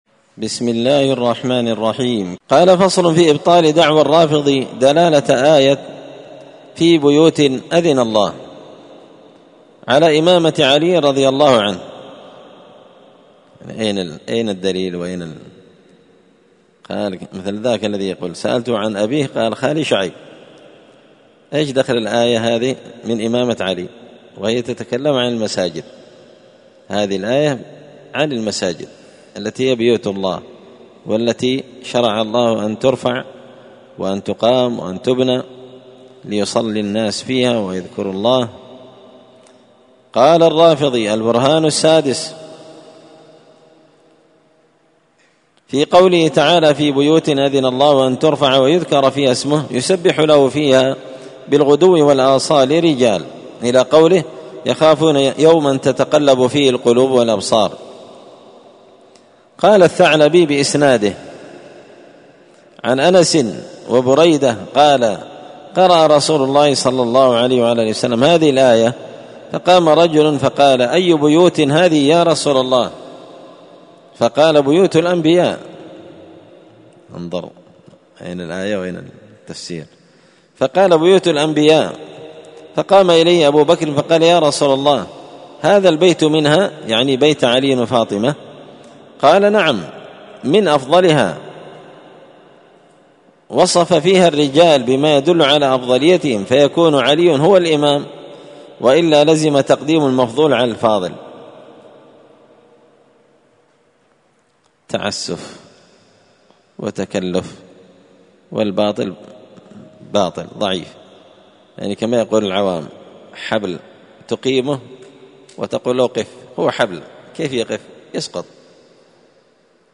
الأربعاء 29 محرم 1445 هــــ | الدروس، دروس الردود، مختصر منهاج السنة النبوية لشيخ الإسلام ابن تيمية | شارك بتعليقك | 63 المشاهدات